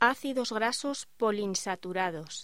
Locución: Ácidos grasos poliinsaturados